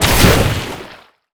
water_blast_projectile_spell_05.wav